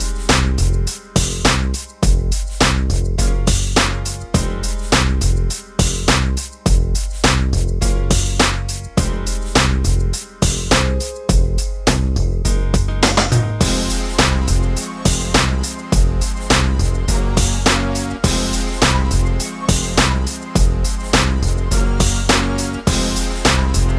no Backing Vocals R'n'B / Hip Hop 3:57 Buy £1.50